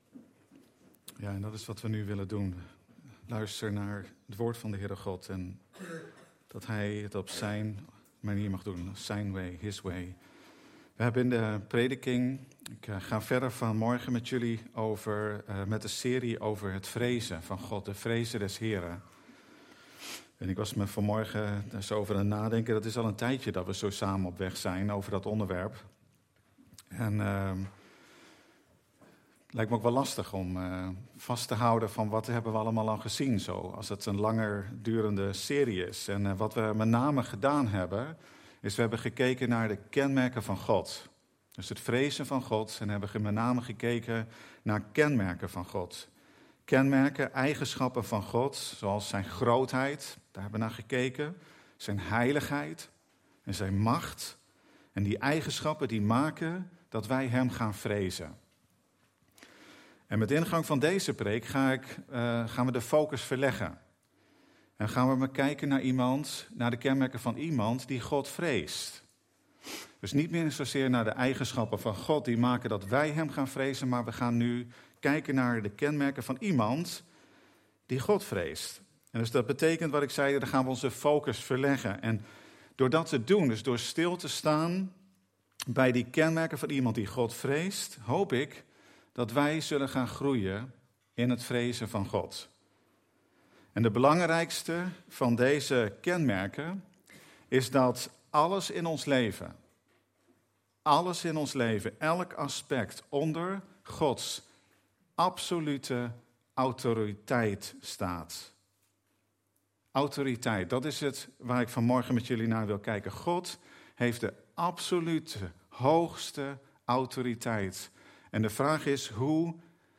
Passage: Mattheus 28:18-19 Dienstsoort: Eredienst